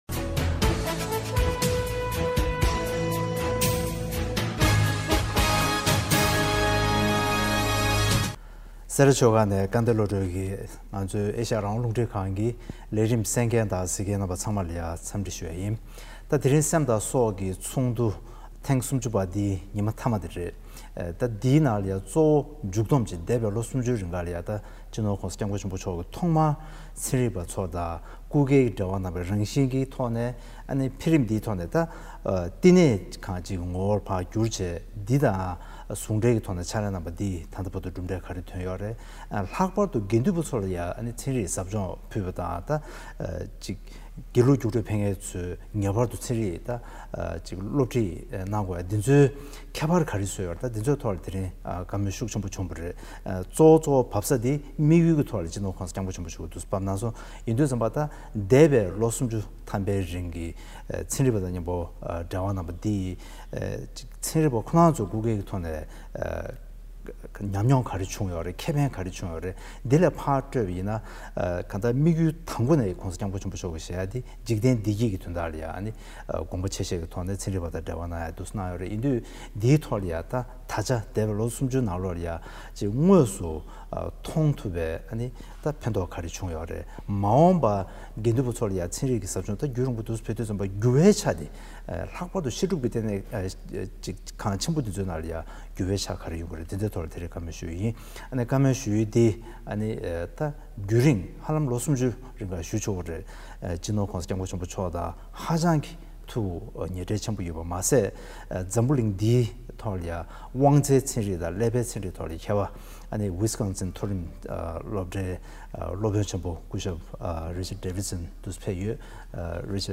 གླེང་མོལ་ཞུས་པ་ཞིག་གསན་རོགས༎